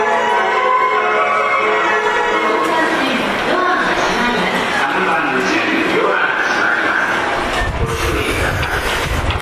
日本の玄関口ですが、メロディーは特に変わっているものでもありません。
発車4音色a --